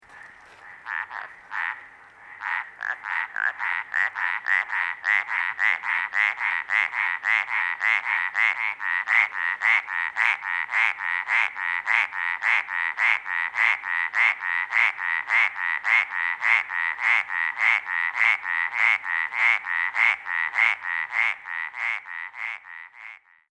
Advertisement Calls
The following sounds were recorded at night in the mountains of Coconino County, Arizona at the
Sound  This is a 23 second recording of the advertisement calls of a close group of Arizona Treefrogs calling at night in Coconino County, Arizona.